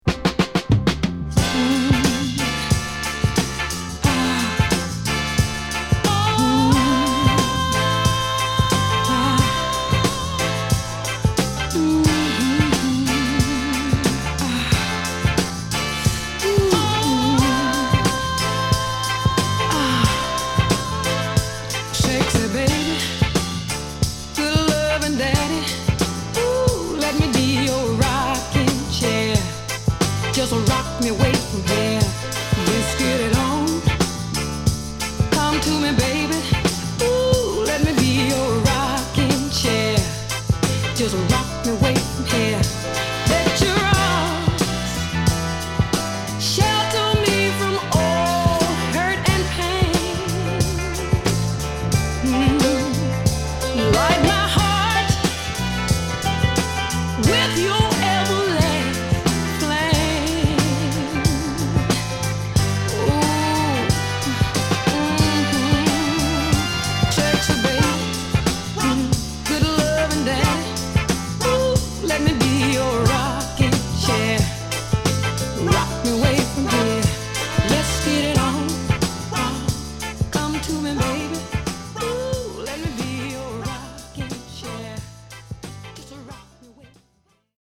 独特の乾いたサウンドと抜けたノリが心地良い、ファンキーなクラシックチューン！